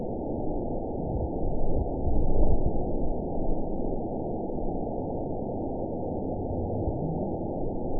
event 917458 date 04/03/23 time 20:58:32 GMT (2 years, 1 month ago) score 8.28 location TSS-AB03 detected by nrw target species NRW annotations +NRW Spectrogram: Frequency (kHz) vs. Time (s) audio not available .wav